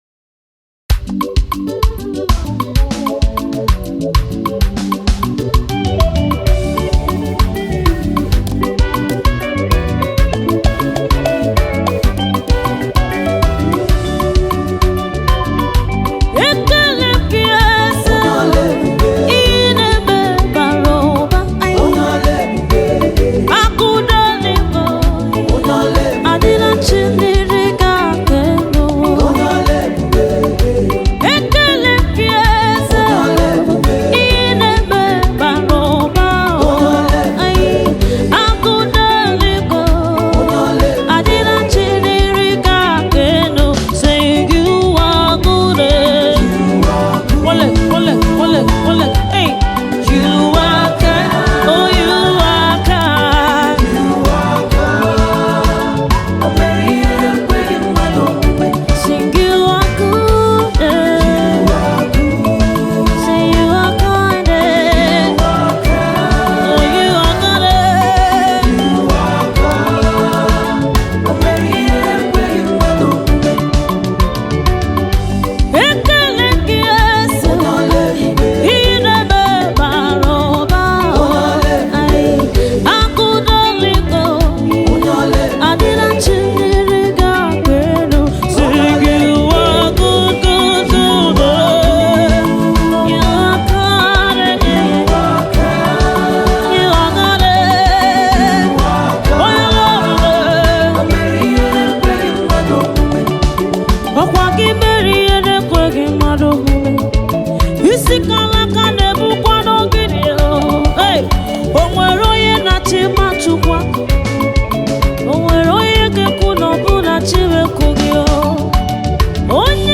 Nigerian Gospel
Genre: Gospel/Christian